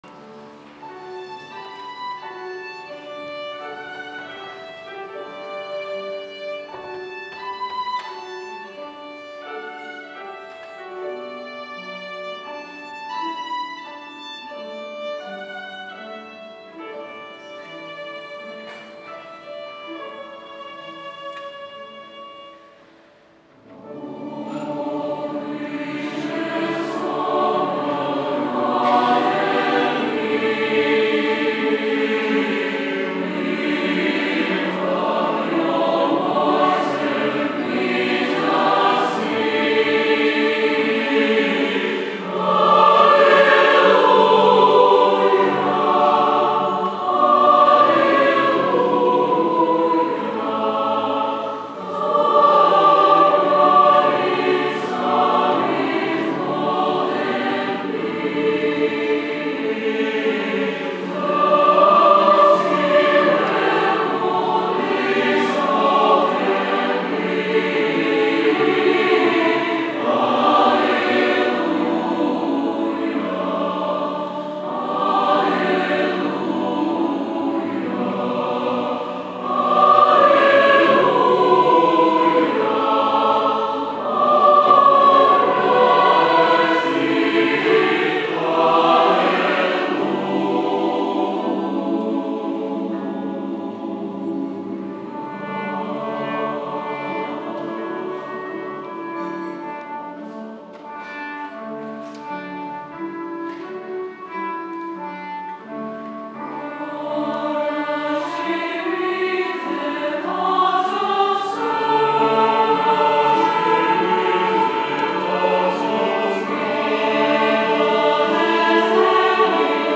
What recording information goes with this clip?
Live MP3